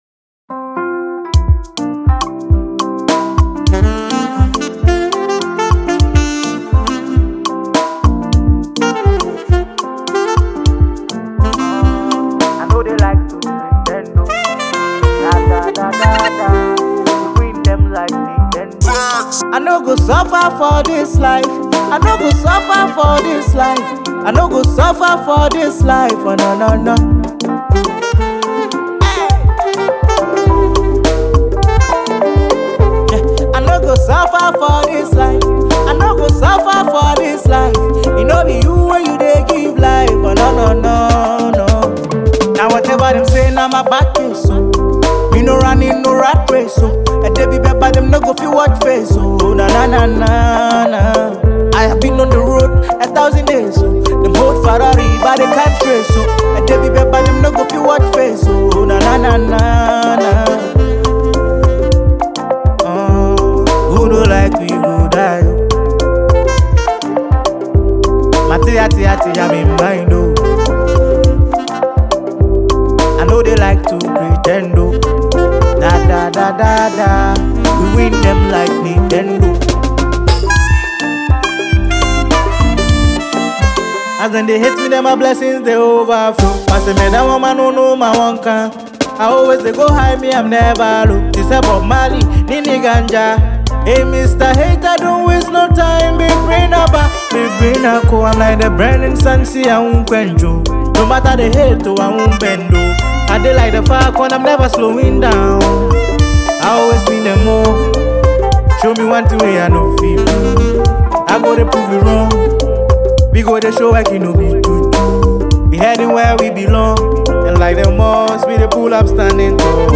drops this inspirational Highlife sound